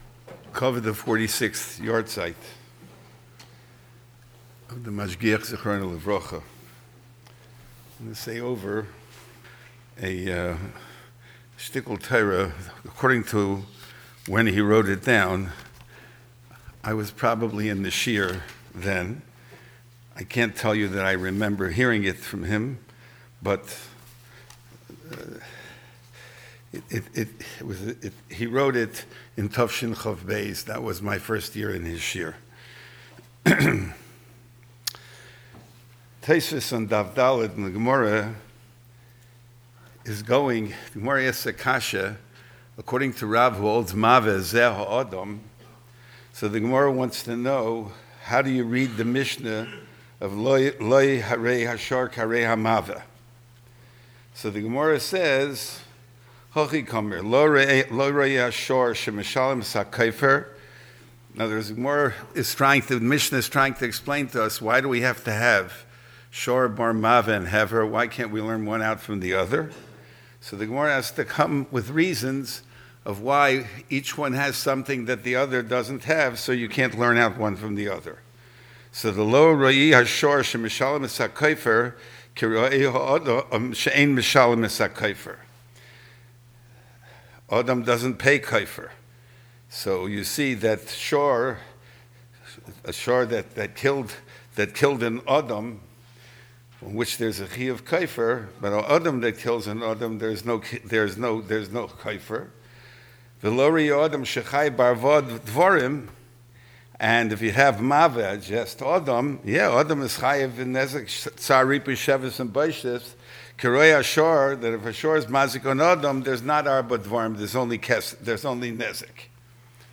Liluy Nishmaso, the Yeshiva held a special seder in the Yeshiva Bais Medrash following the Taanis.